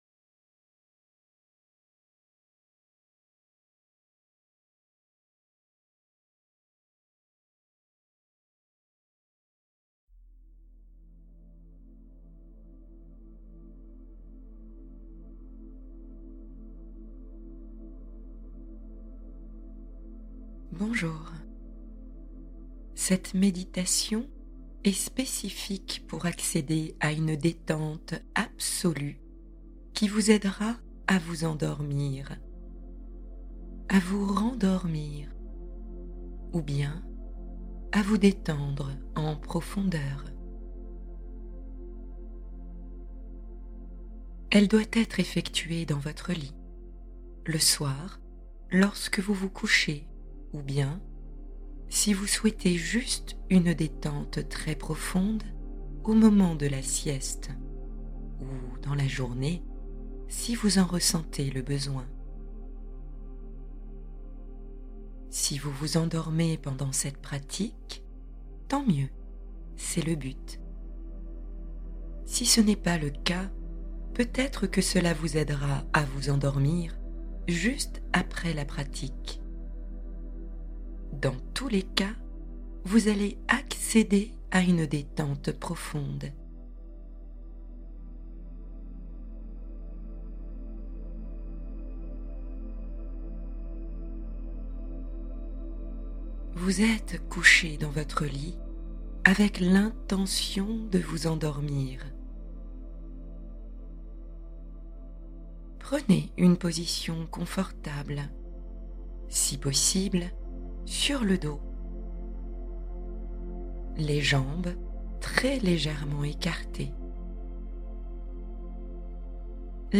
Détente Absolue : S'endormir naturellement par la relaxation guidée